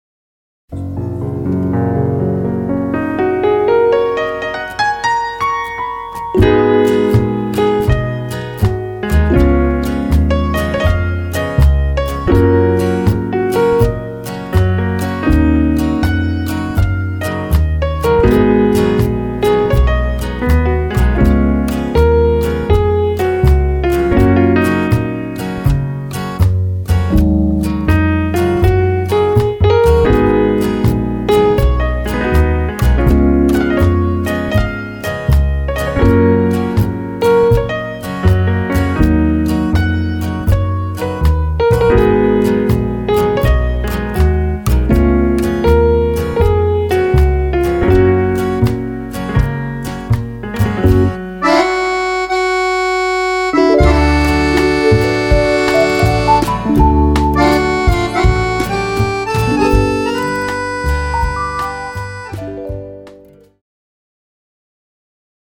Cued Sample
Foxtrot/Rumba